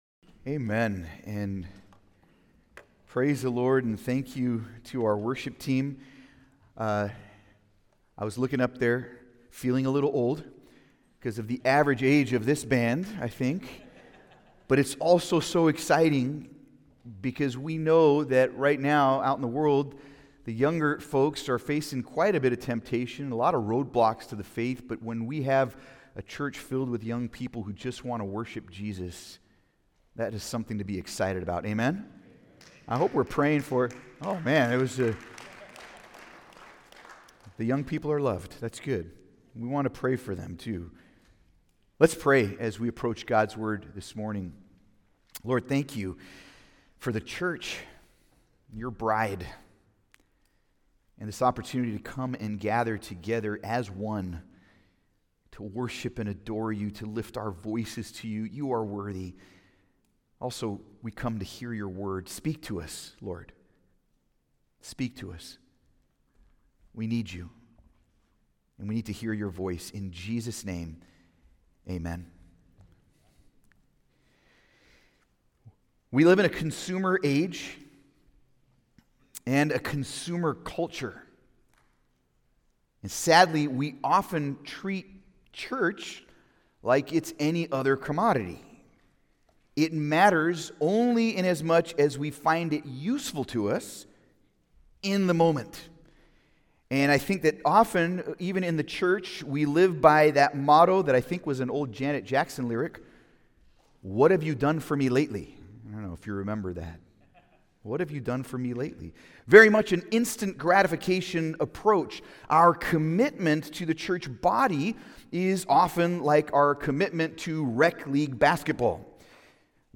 Service Type: Sunday Service
Sermon on 1 Thessalonians 5:9-15